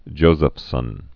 (jōzəf-sən, -səf-)